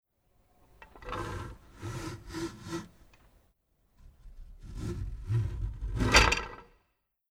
48999_Oeffnen_und_schliessen.mp3